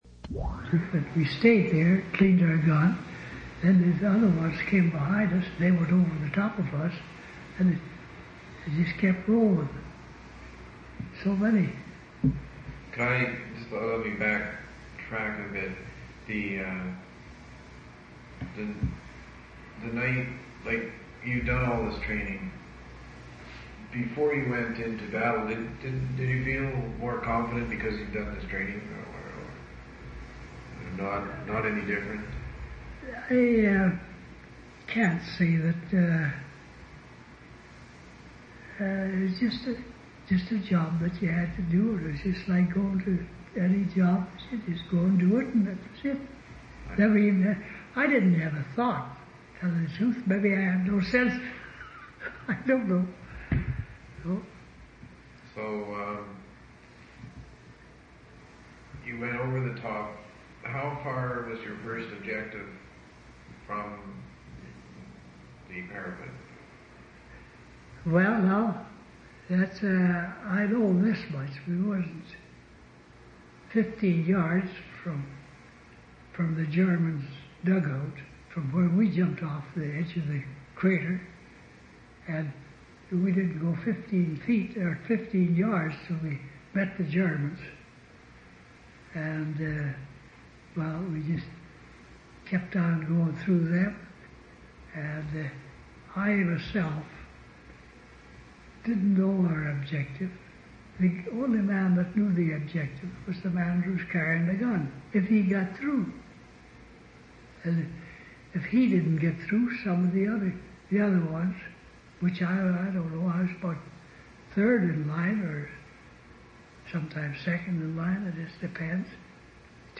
Interview took place on June 11, 1977.